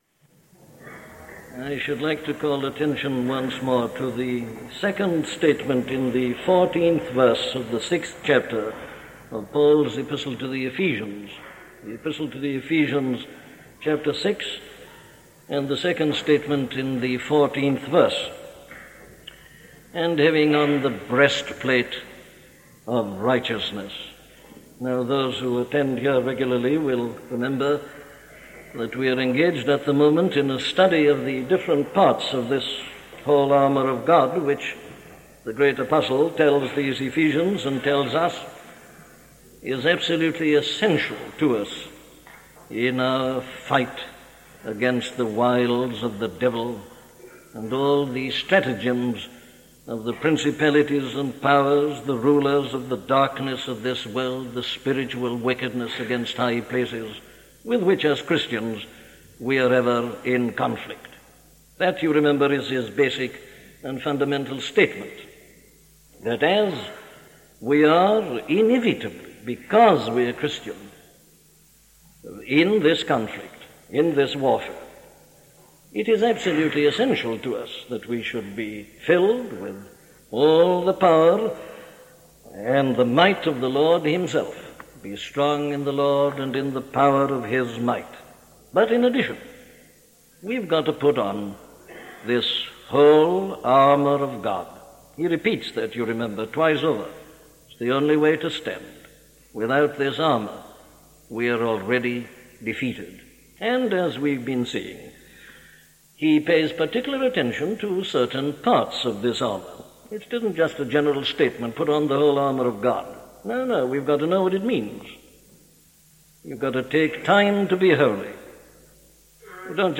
Putting on the Breastplate - a sermon from Dr. Martyn Lloyd Jones
Listen to the sermon on Ephesians 6:14 'Putting on the Breastplate' by Dr. Martyn Lloyd-Jones